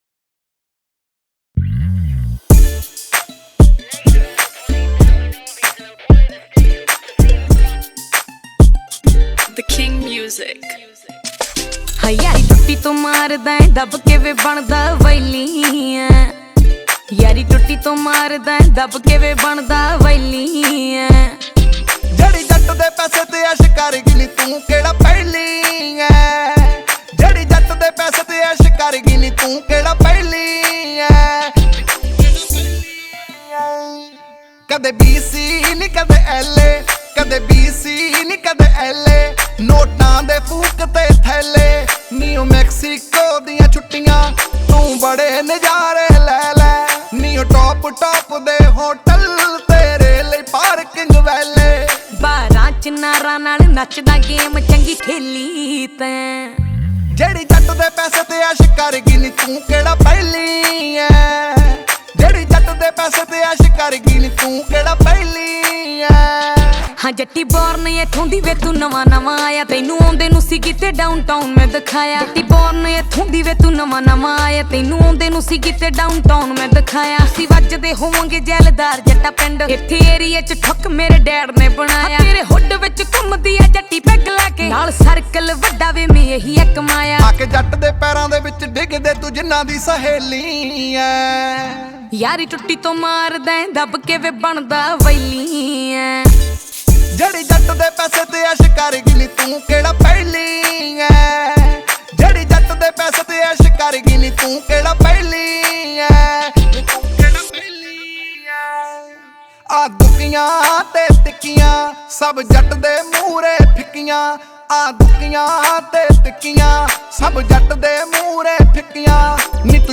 Category: Punjabi Singles